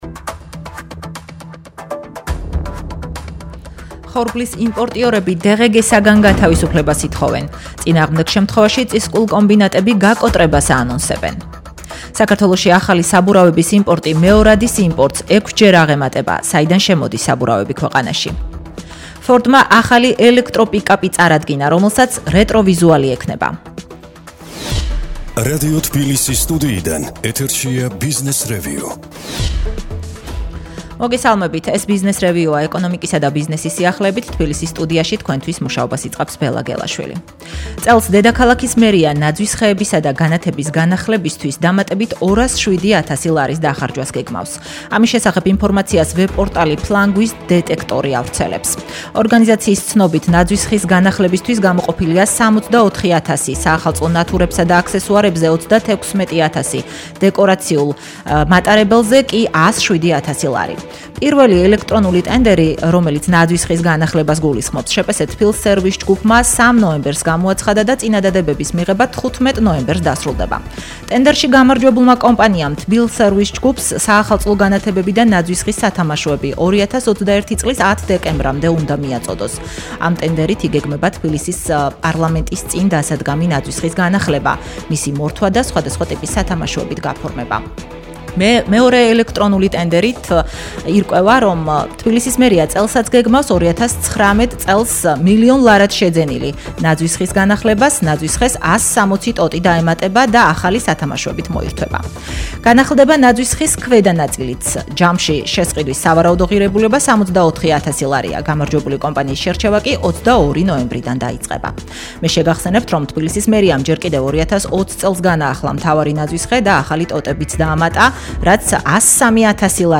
მოუსმინეთ გადაცემა”ბიზნესრევიუს”, ბიზნესისა და ეკონომიკის სიახლეების შესახებ. ყოველდღღე რადიოების: თბილისის FM93.5, ჰერეთის FM102.8 და ციტრუსის FM97.3 ეთერში.